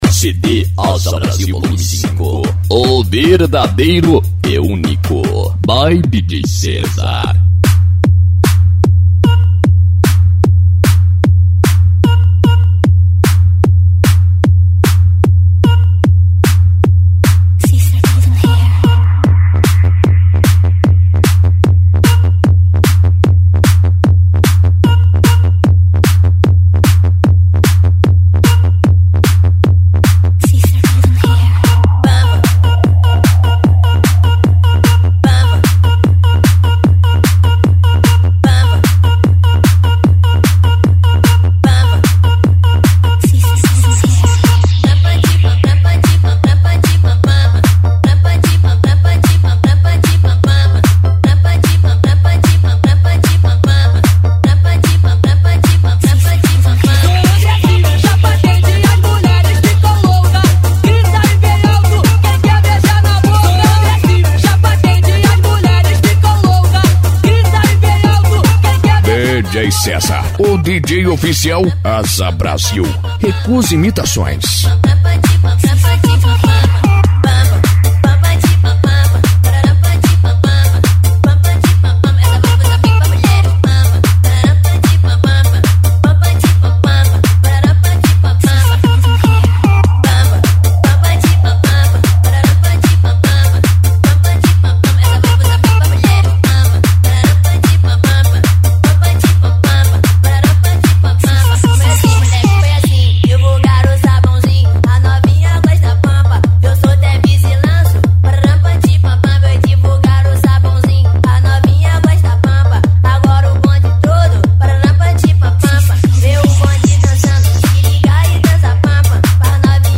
PANCADÃO
Funk
Electro House